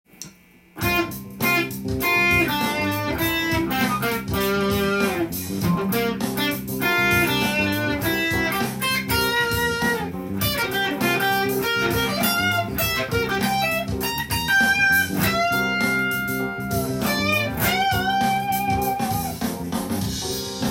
この手法でギターソロを弾いてみました。
c7.solo_.tab_.m4a